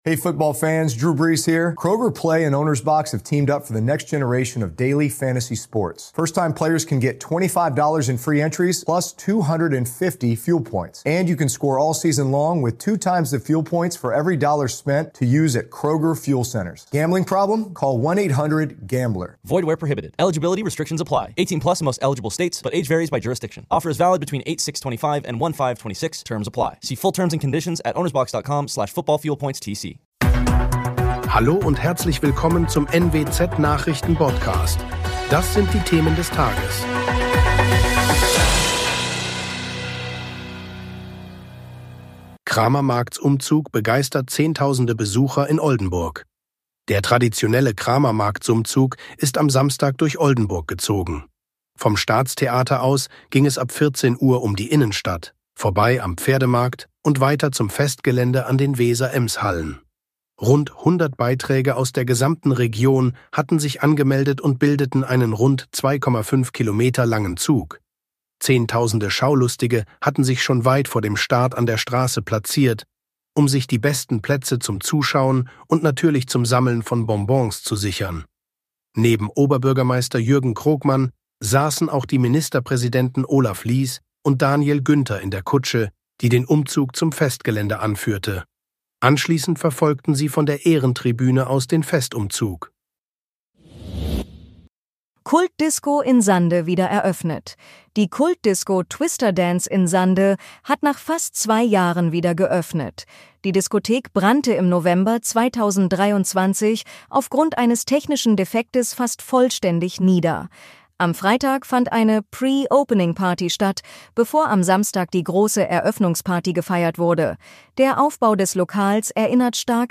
NWZ Nachrichten Botcast – der tägliche News-Podcast aus dem Norden
Nachrichten